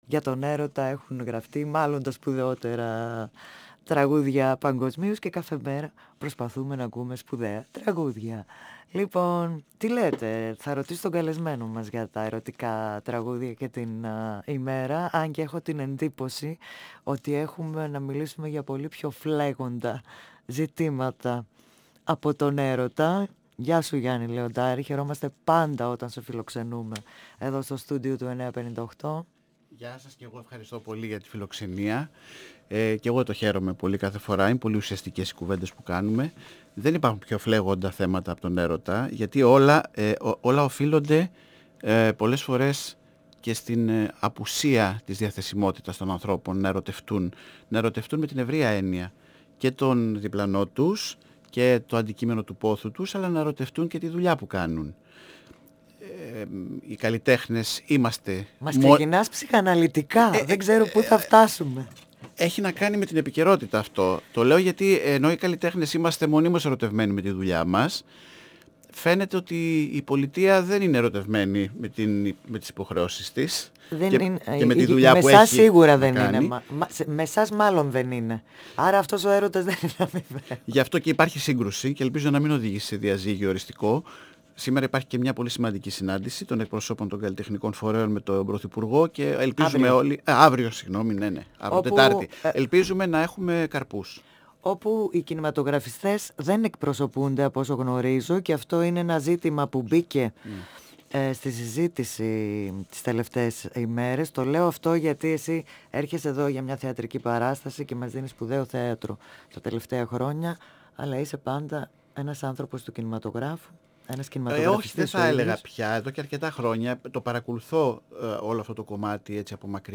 καλεσμένος στο στούντιο του 9.58